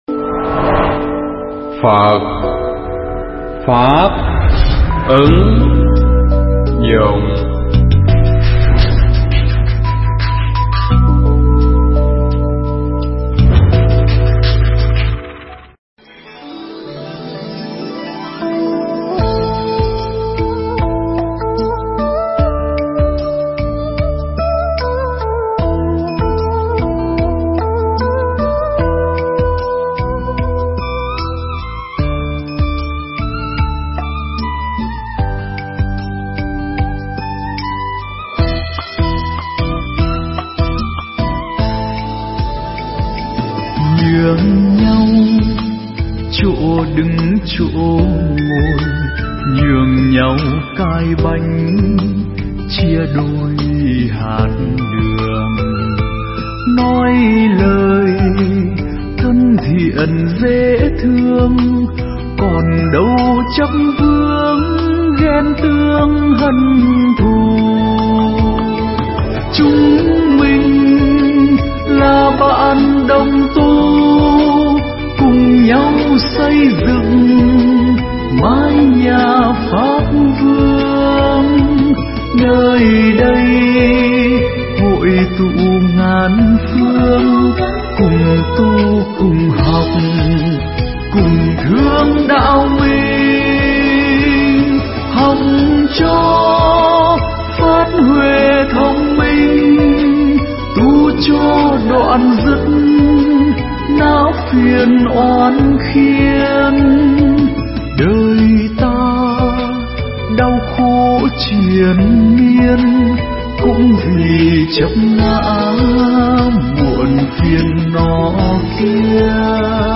Nghe Mp3 thuyết pháp Giận Hờn Vu Vơ
Mp3 pháp thoại Giận Hờn Vu Vơ